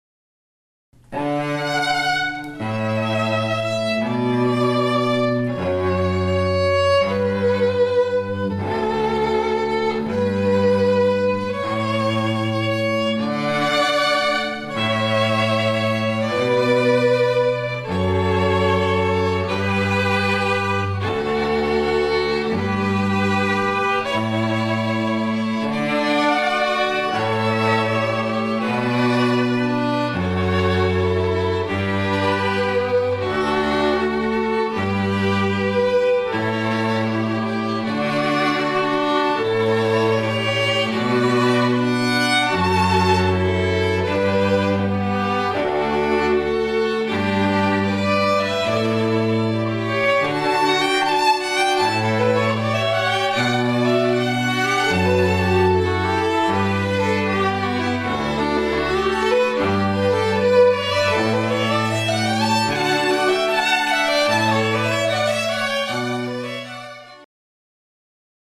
Canon in D Pachelbel String Quartet